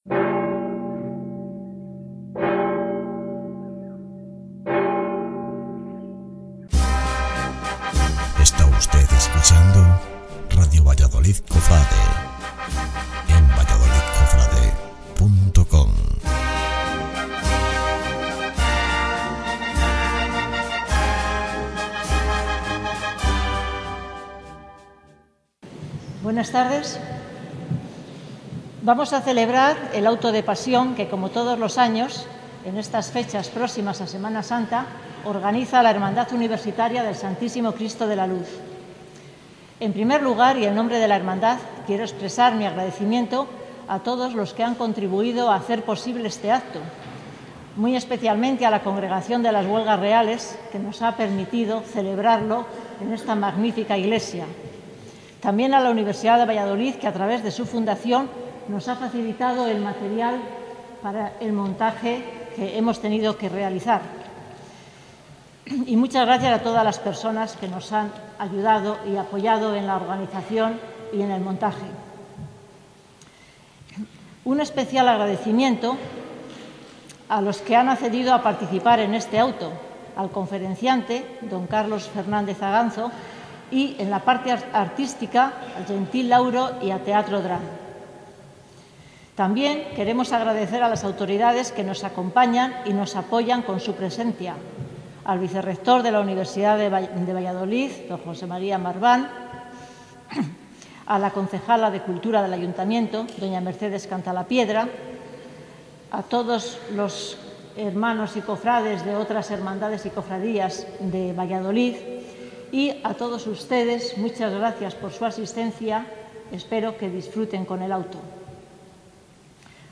Iglesia de las Huelgas Reales